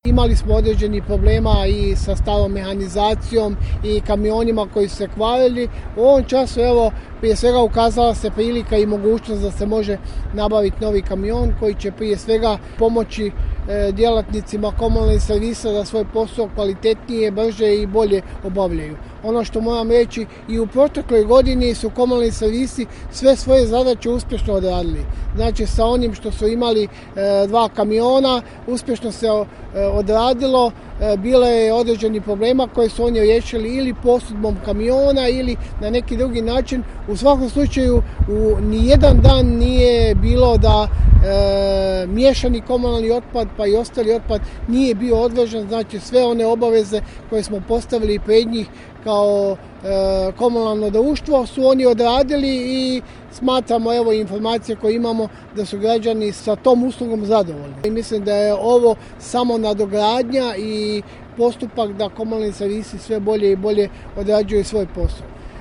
Da je riječ o nastavku opremanja KSP-a, a čemu se godinama teži, potvrdio je gradonačelnik Popovače Josip Mišković.